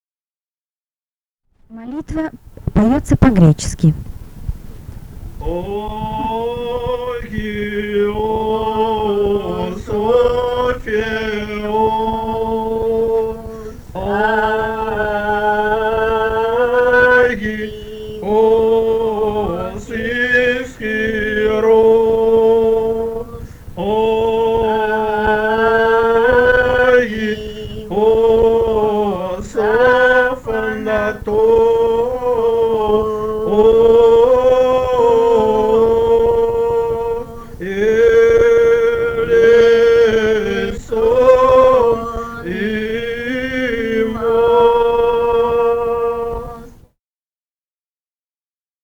«Агиос (Святый Боже)» (молитва).
Архангельская область, с. Койда Мезенского района, 1965, 1966 гг.